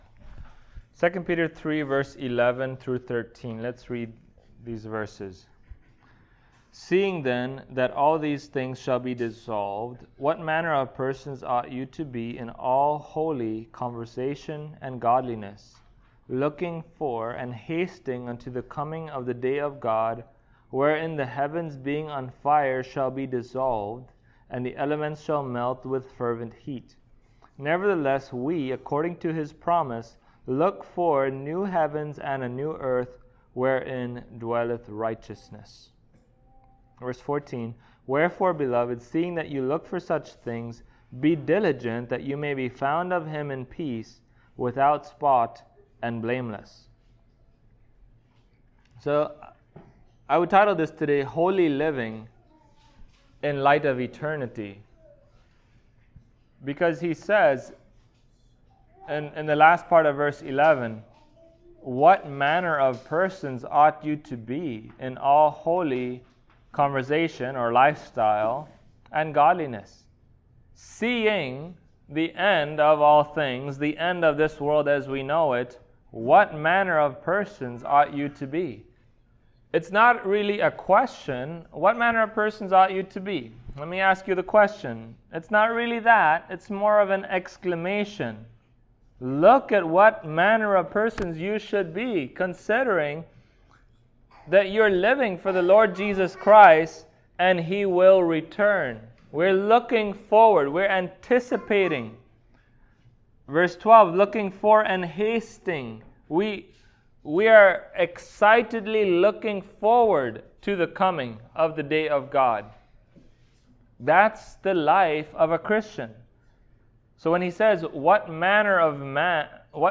2 Peter Passage: 2 Peter 3:11-13 Service Type: Sunday Morning Topics